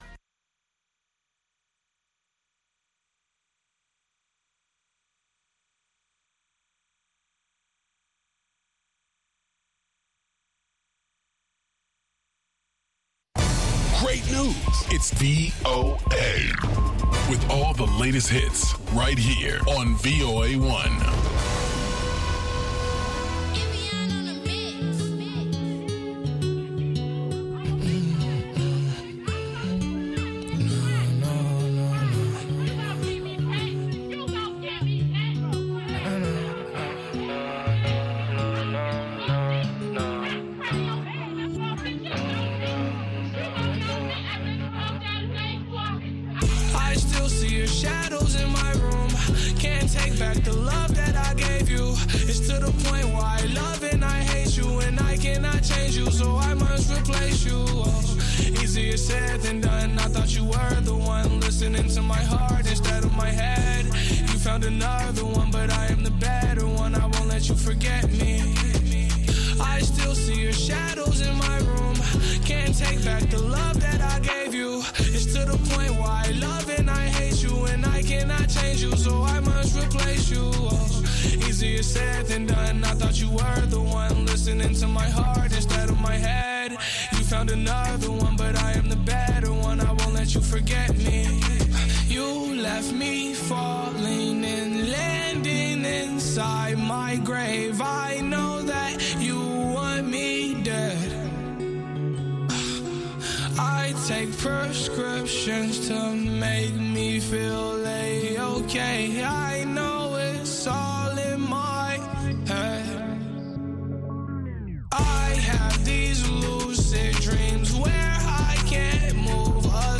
Cápsula informativa de tres minutos con el acontecer noticioso de Estados Unidos y el mundo.